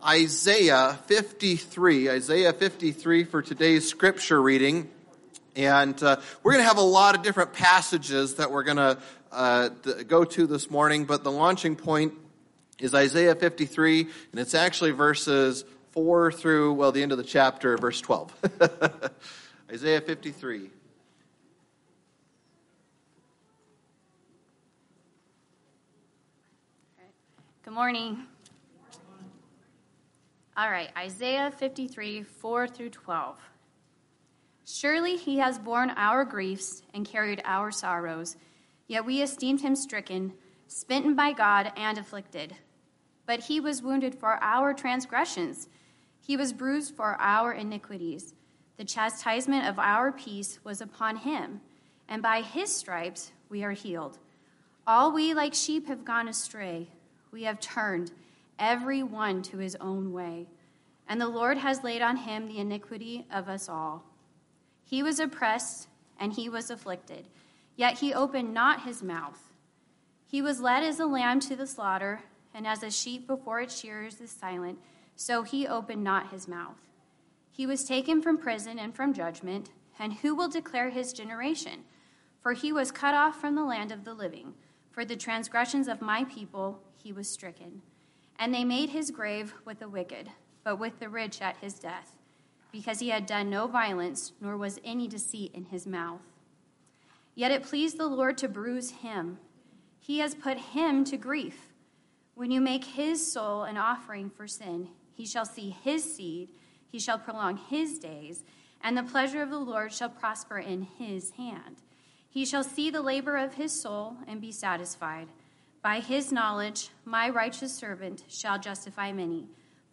Past Sermons - Kuna Baptist Church